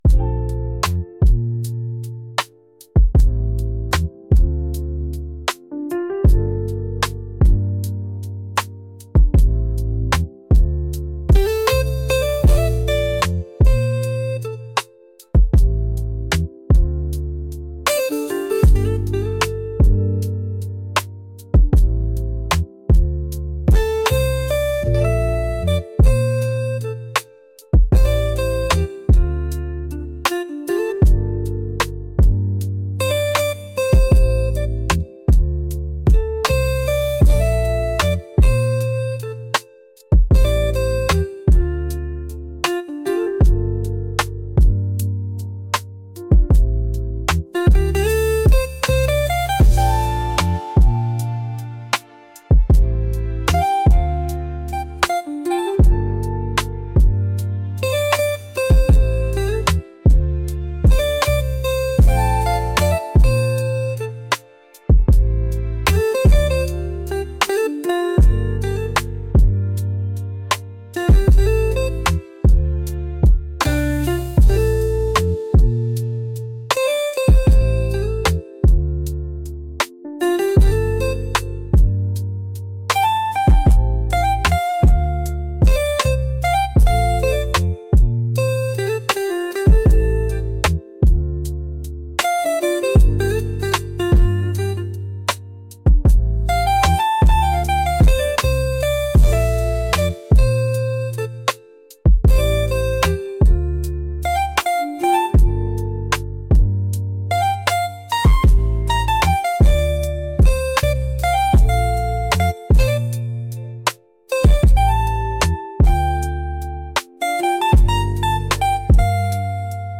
romantic | soulful